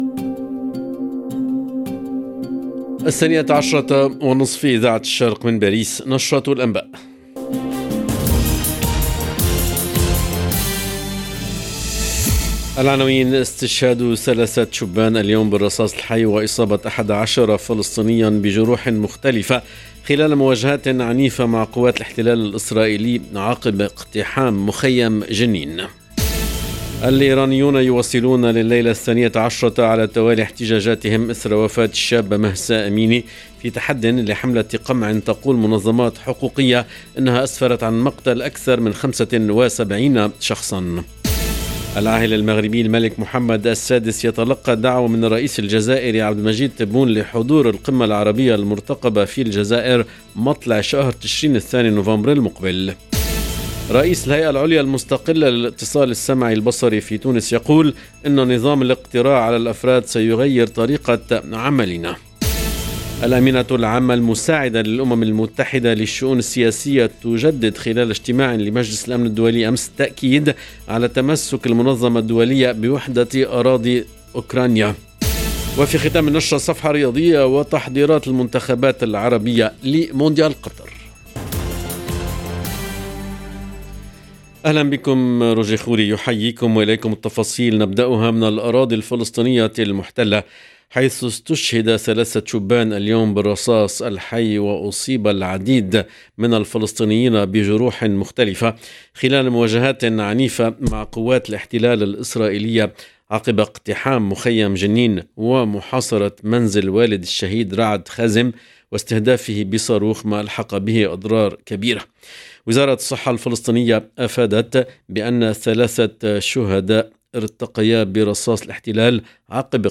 LE JOURNAL DE 12H30 EN LANGUE ARABE DU 28/9/2022